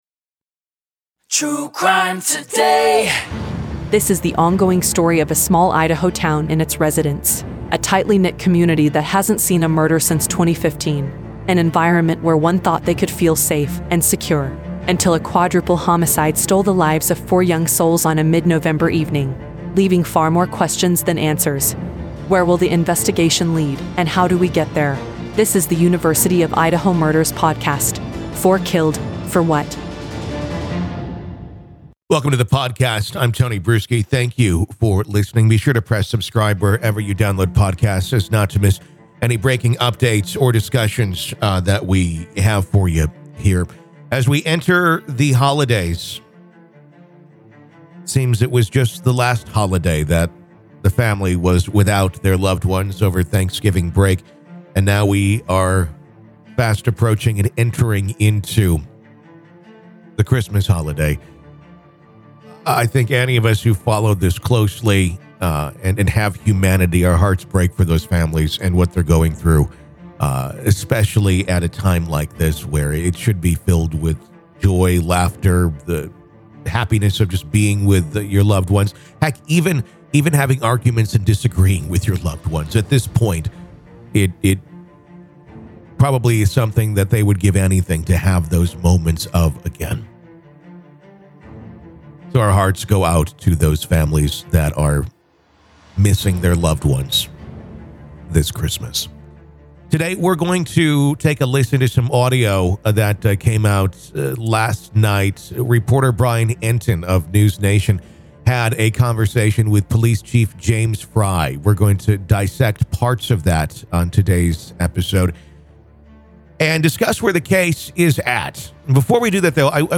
Today we discuss some of the critical points in the case regarding the trust of the families and the public in the investigation. We listen to audio from News Nation's Brian Entin and his interview with Moscow Police Chief James Fry.